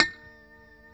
E5 PICKHRM1D.wav